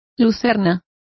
Complete with pronunciation of the translation of lucerne.